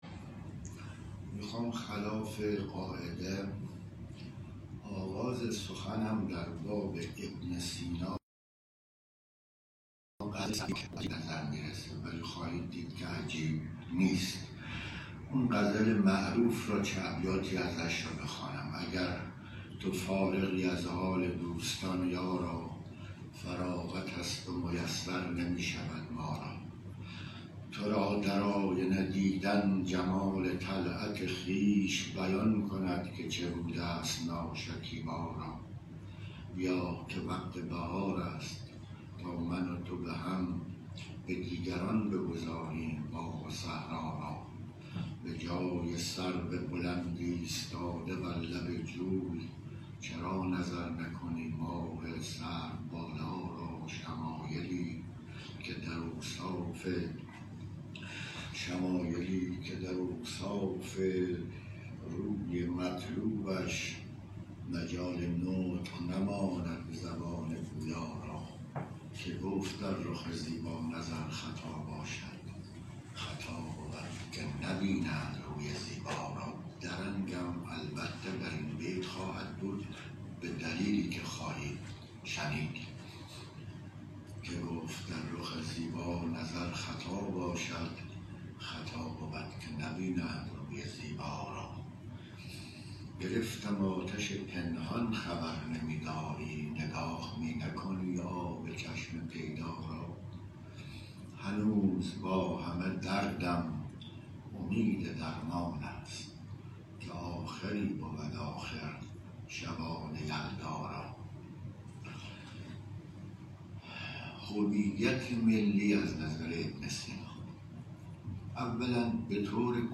سی‌وهشتمین نشست از مجموعه درس‌گفتارهایی درباره بوعلی‌سینا
این درس‌گفتار به صورت مجازی از اینستاگرام شهر کتاب پخش شد.